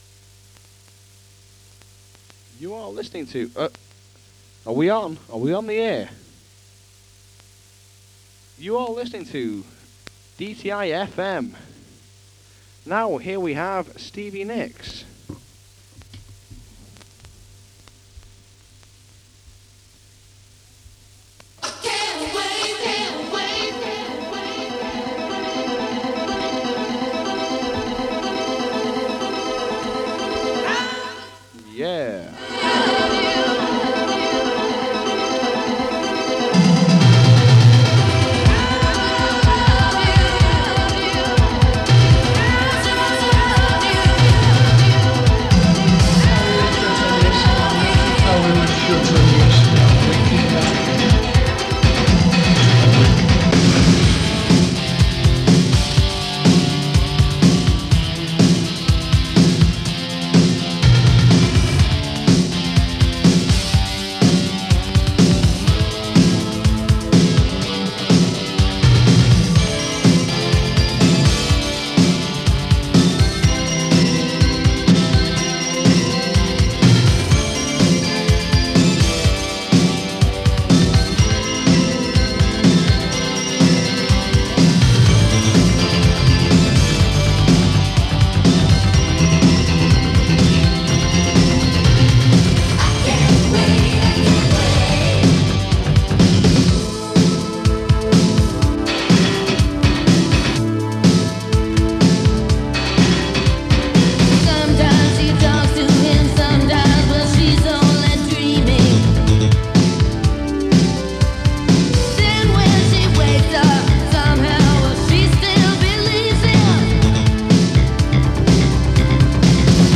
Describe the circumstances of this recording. Recorded near Blackpool from 105.8MHz in mono. 51MB 56mins